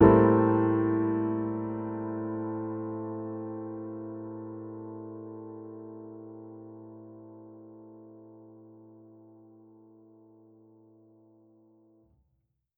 Index of /musicradar/jazz-keys-samples/Chord Hits/Acoustic Piano 1
JK_AcPiano1_Chord-Am7b9.wav